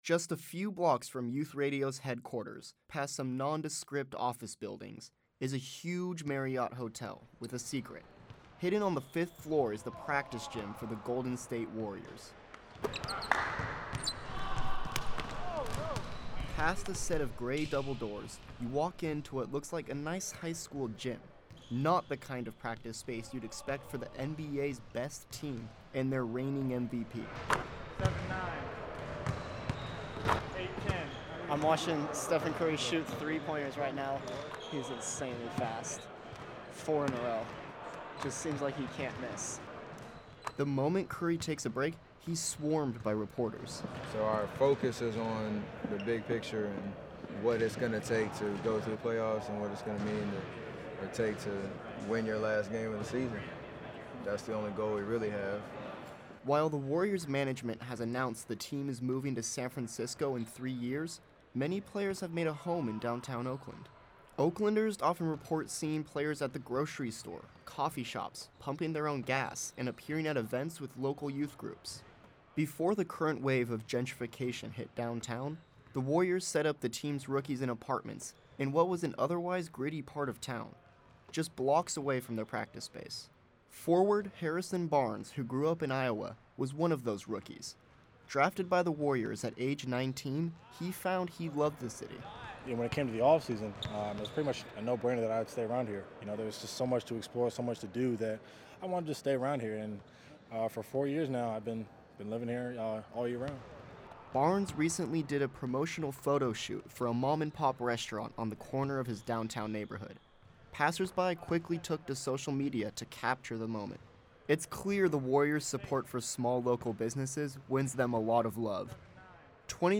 A Youth Radio Sports exclusive featuring interviews with the Golden State Warriors’ Harrison Barnes and James McAdoo.